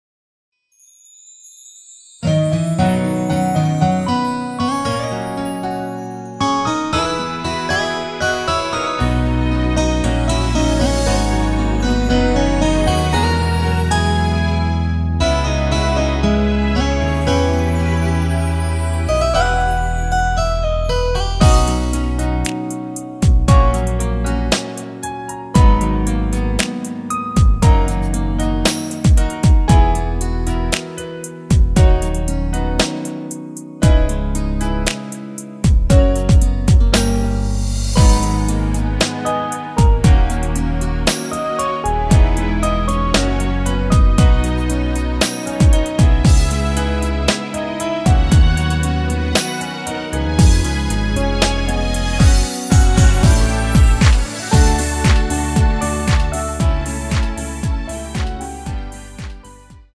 MR 입니다.
원곡의 보컬 목소리를 MR에 약하게 넣어서 제작한 MR이며